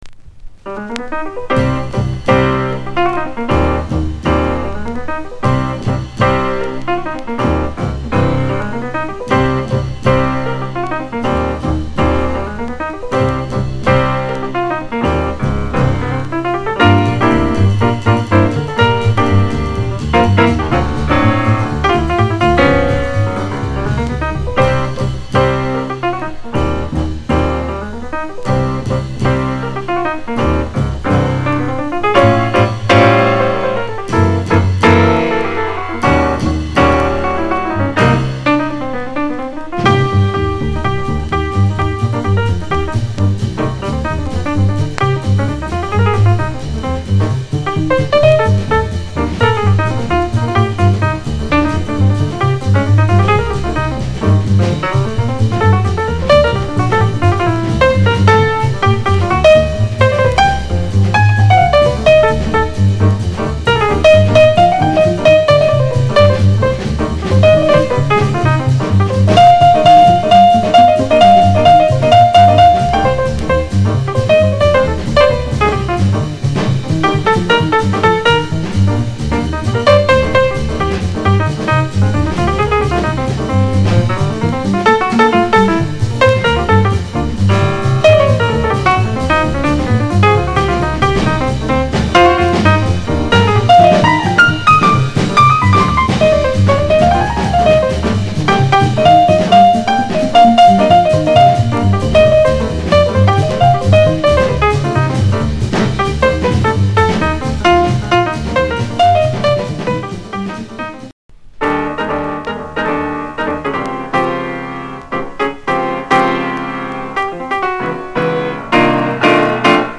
discription:Stereo高音質盤
本盤は60年代にプレスされたセカンドですが、コレクターの間で昔から非常に高音質で評価の高い一枚。